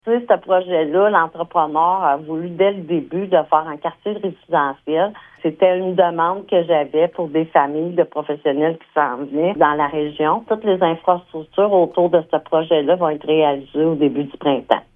Le développement comprendra 22 terrains dont certains ont déjà trouvé preneurs. La mairesse de Maniwaki, Francine Fortin, est particulièrement fière de ce projet :